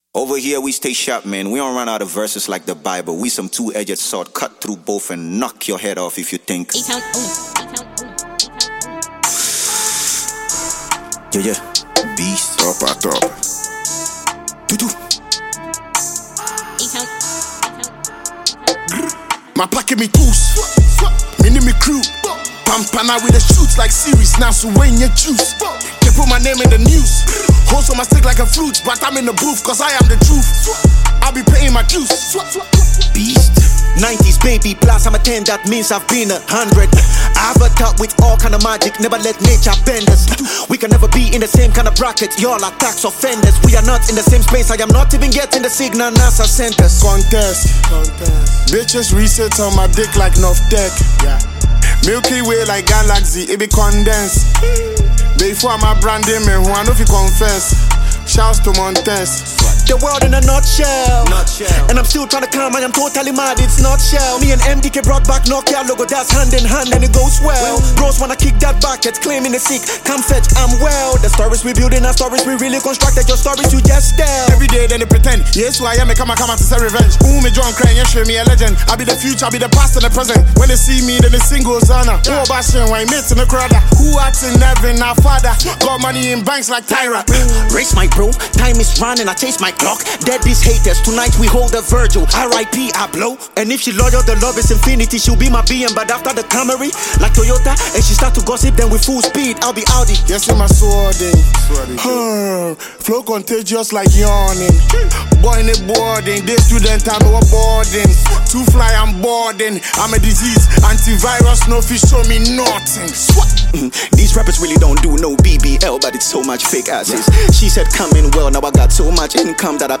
Ghana’s finest rappers
hard-hitting